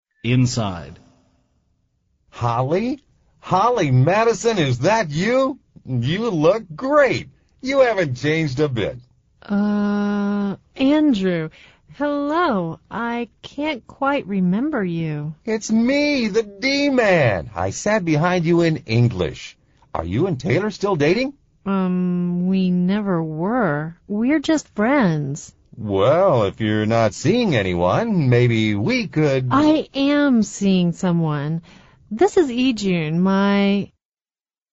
美语会话实录第122期(MP3+文本):Haven't changed a bit